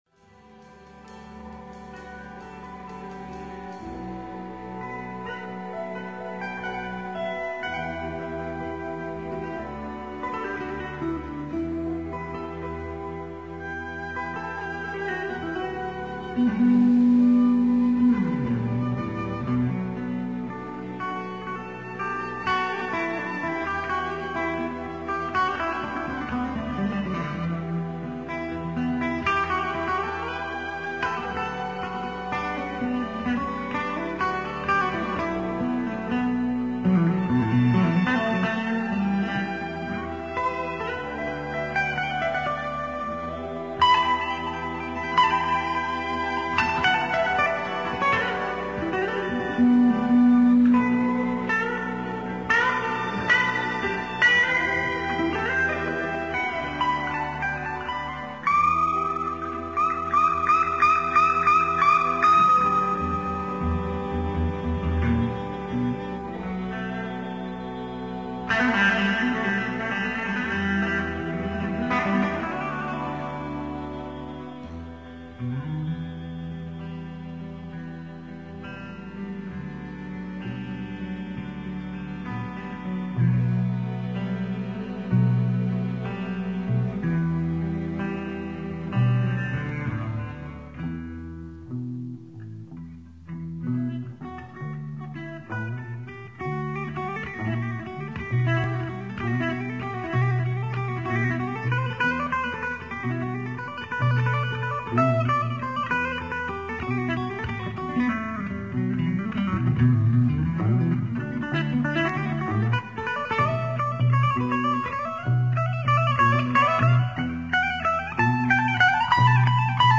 en DIRECTO en 1972.